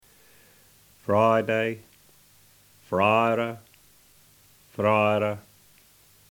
Puhoi Egerländer Dialect